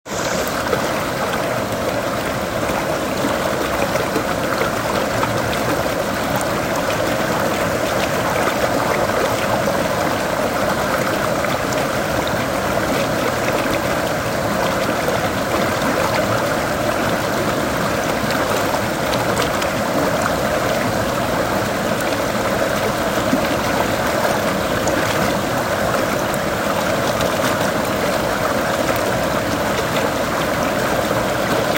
The river played a major part in my experience at the farm.
ritmo_del_rio_river.m4a